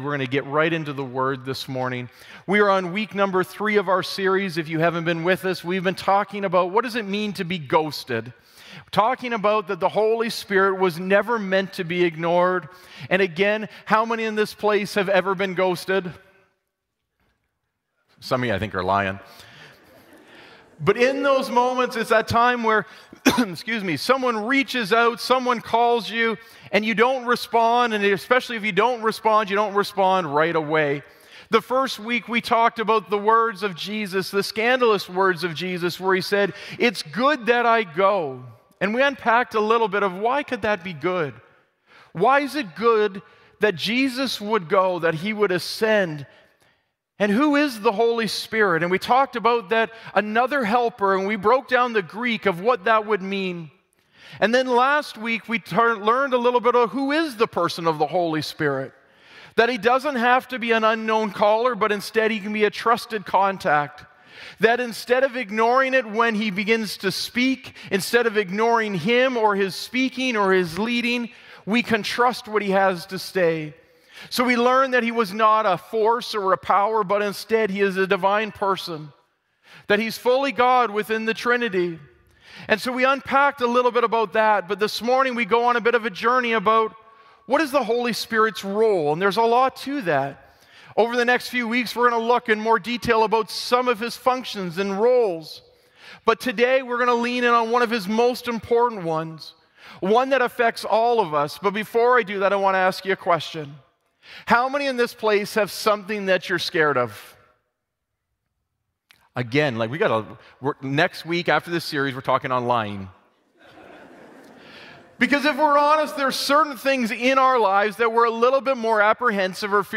Sermon Podcast